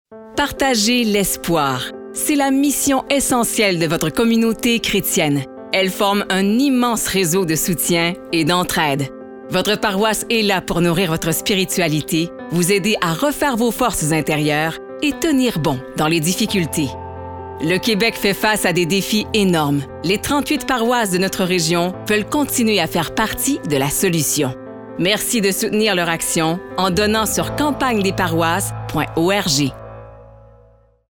Publicité à la radio